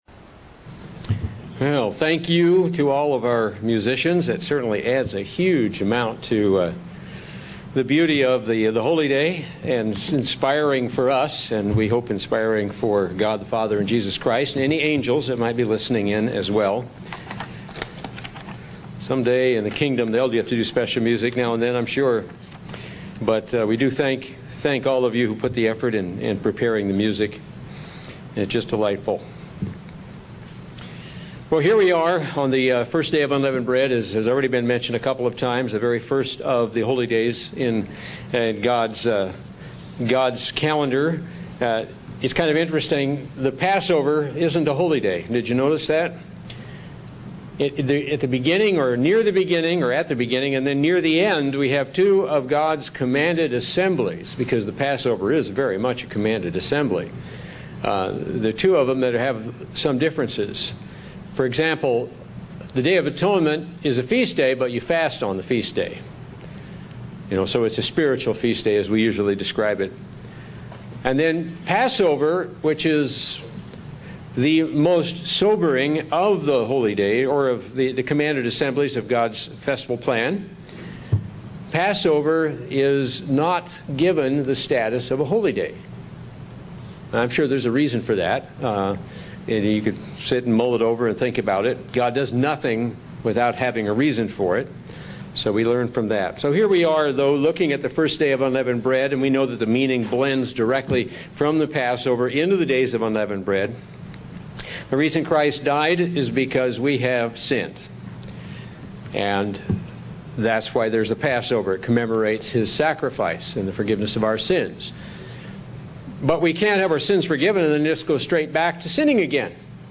Sermons
Given in North Canton, OH